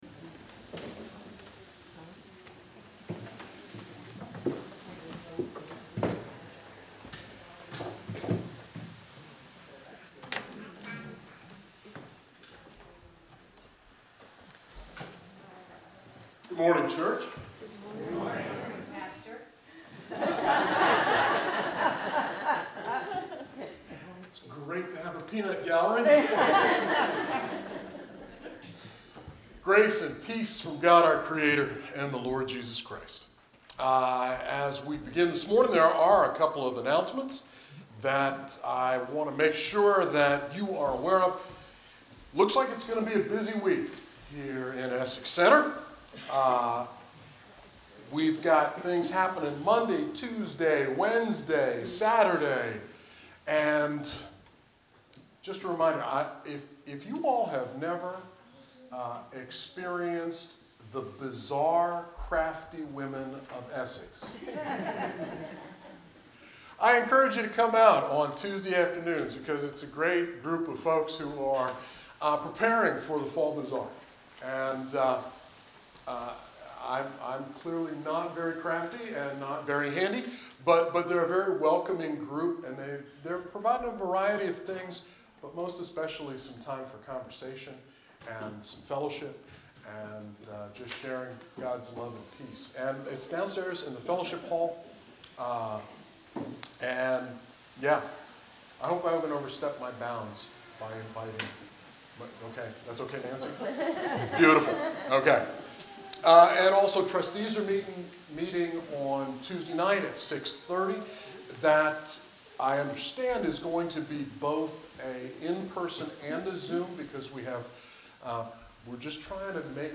You are welcome to join us for in-person worship in the Essex Center UMC sanctuary, you may participate on Facebook Live by clicking here,Read more